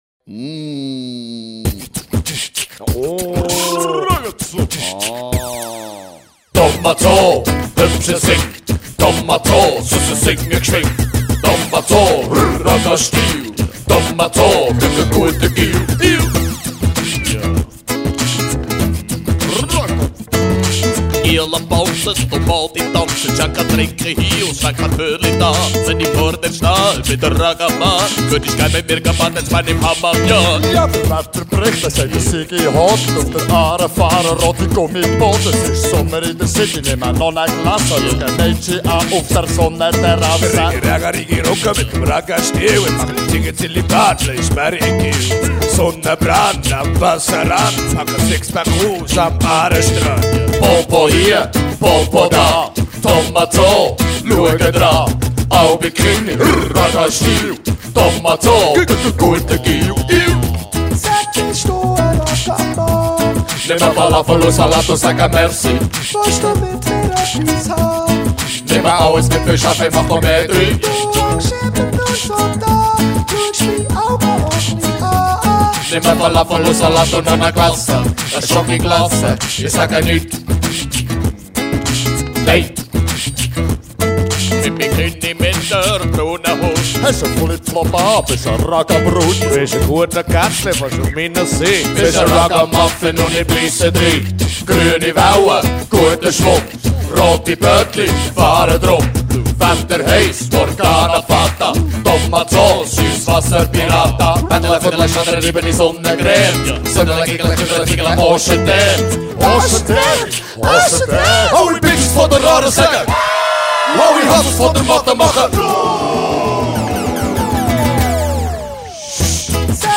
Entertainment / songwriter.
strummed guitars